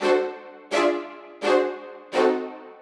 Index of /musicradar/gangster-sting-samples/85bpm Loops
GS_Viols_85-D.wav